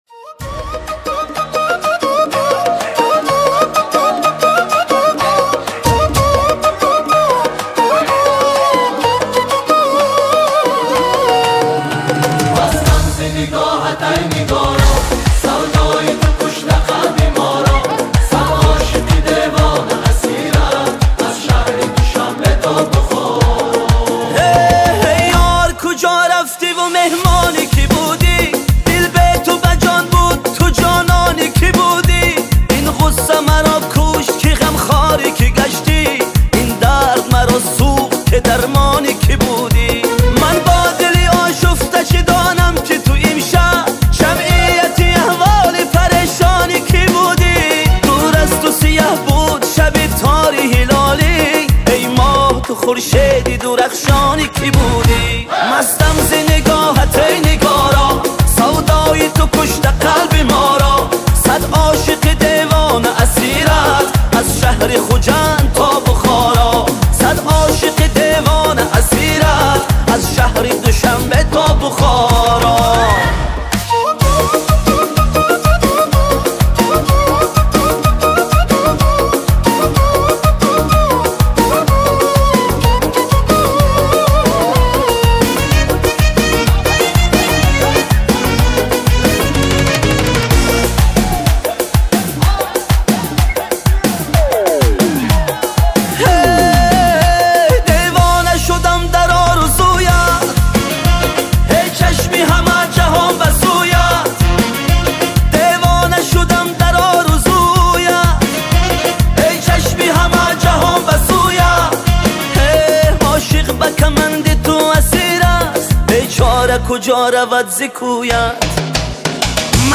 Музыка / 2025-год / Таджикские / Поп